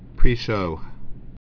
(prēshō)